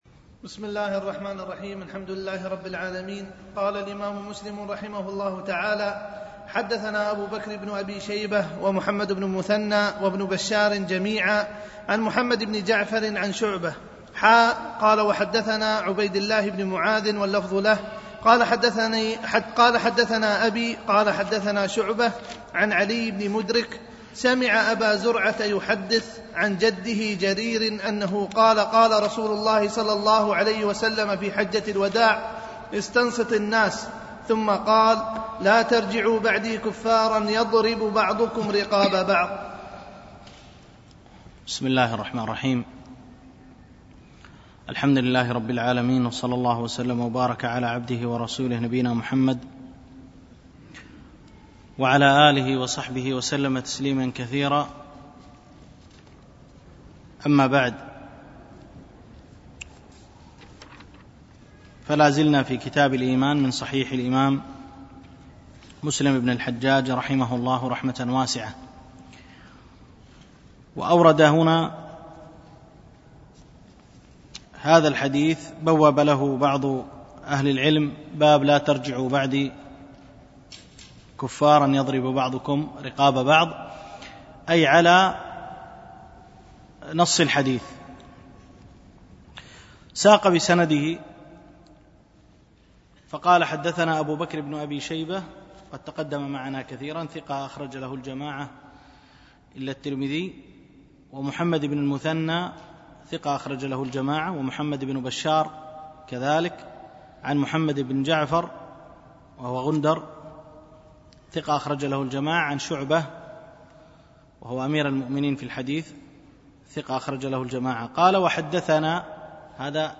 دروس مسجد عائشة
MP3 Mono 22kHz 32Kbps (CBR)